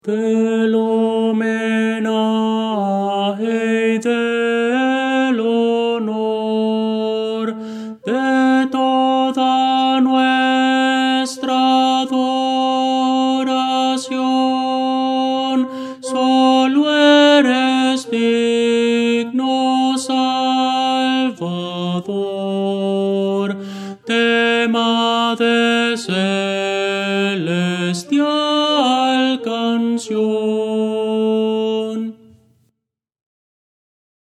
Voces para coro
Tenor – Descargar
Audio: MIDI